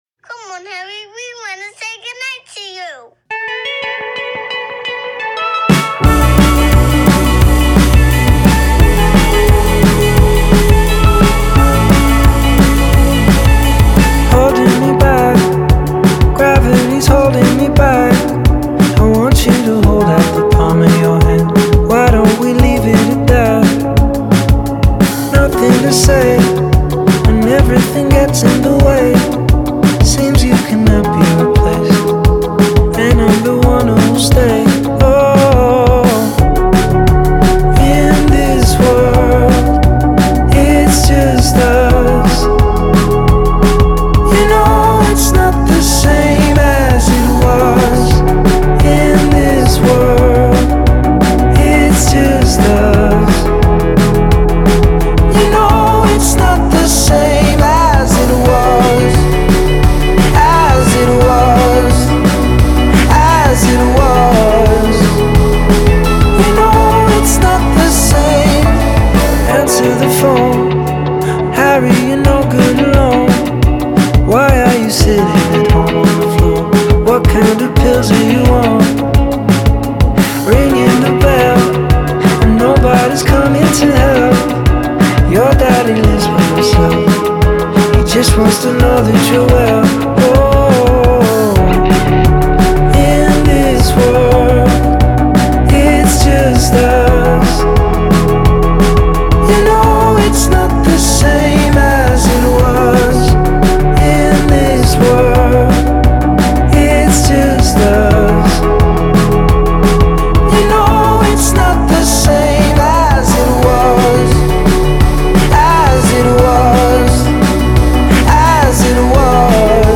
Немного косит, конечно, под A-ha, Take on me))